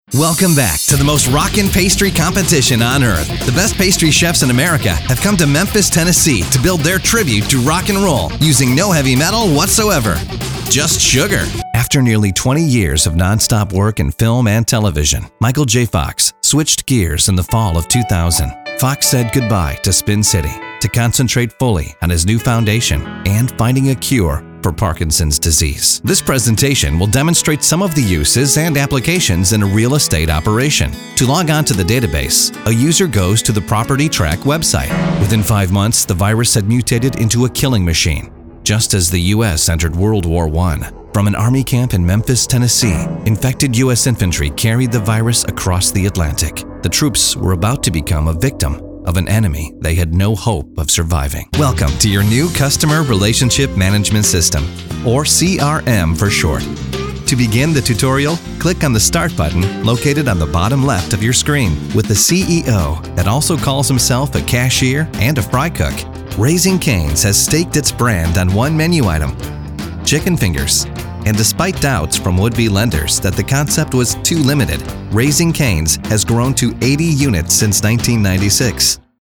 Young, energetic, believable, genuine, real, guy next door
englisch (us)
Kein Dialekt
Sprechprobe: Industrie (Muttersprache):